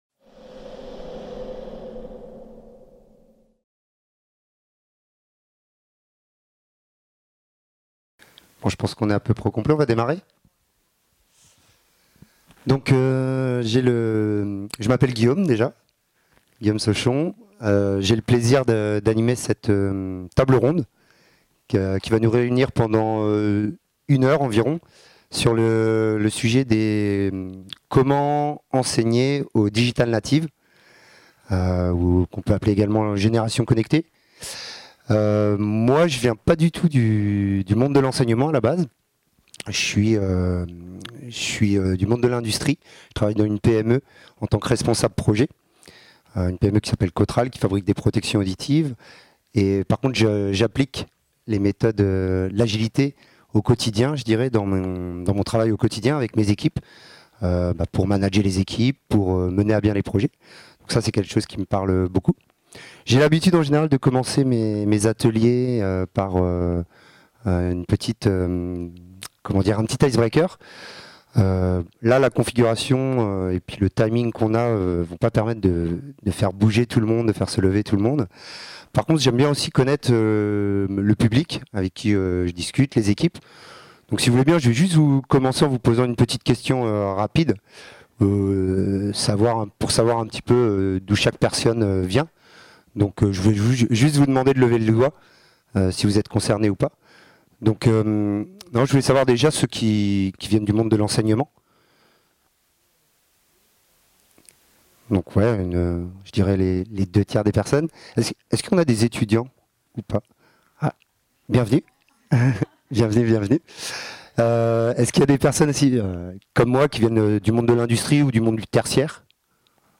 PA2019 | 06 - Atelier 2 : Comment enseigner aux Digital natives ? (Table ronde ouverte ) | Canal U
Lors cette table ronde ouverte, nous aurons un échange ouvert et pragmatique sur la transformation qui s’opère actuellement dans le monde de l’enseignement.